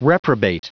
Prononciation du mot reprobate en anglais (fichier audio)
Prononciation du mot : reprobate